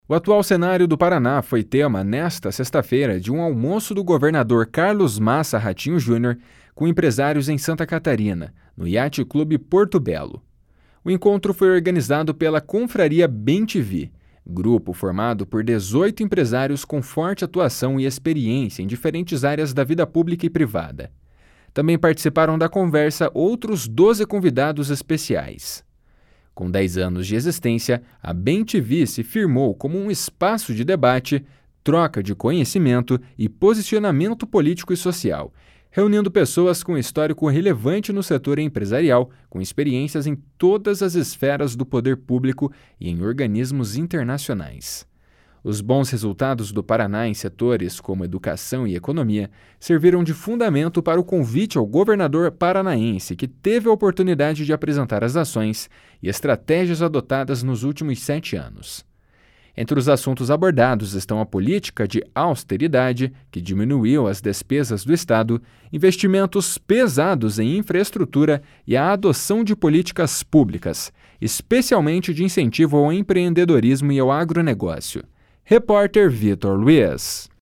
Entre os assuntos abordados estão a política de austeridade que diminuiu as despesas do Estado, investimentos pesados em infraestrutura e a adoção de políticas públicas, especialmente de incentivo ao empreendedorismo e ao agronegócio. (Repórter